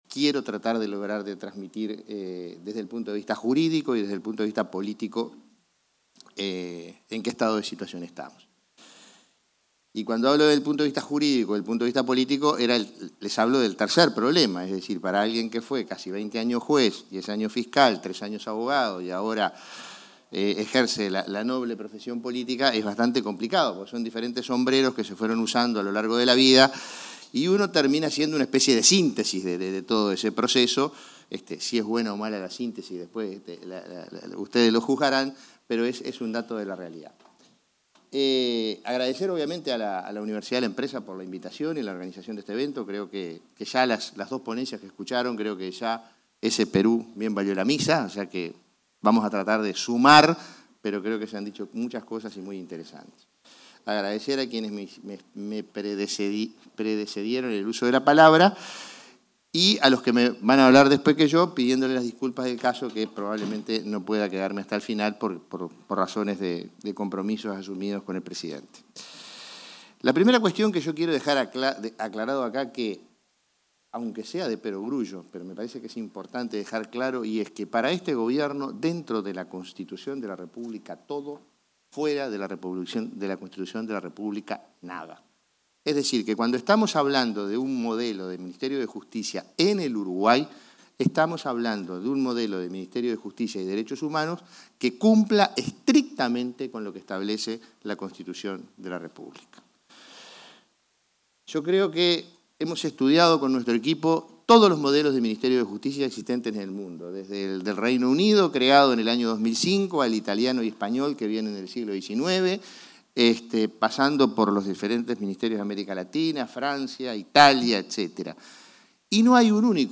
Palabras del prosecretario de la Presidencia, Jorge Díaz
El prosecretario de la Presidencia, Jorge Díaz, expuso en el seminario internacional La Creación de un Ministerio de Justicia y Derechos Humanos en